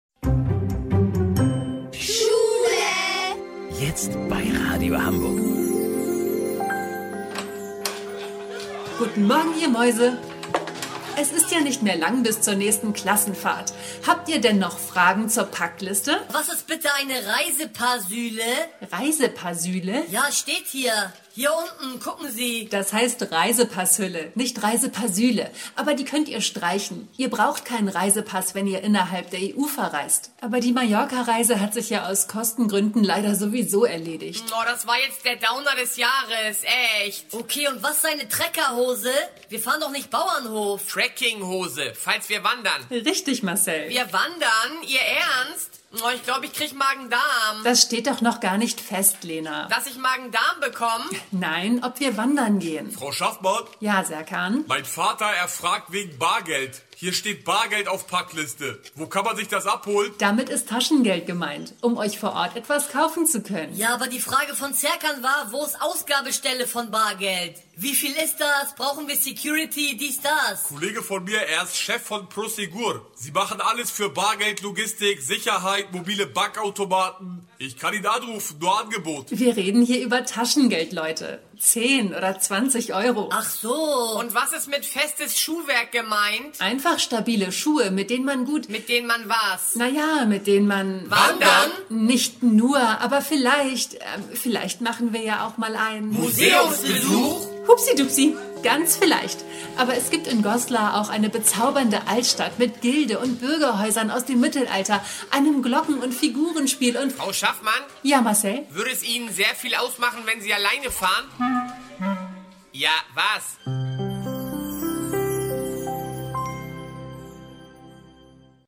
Comedy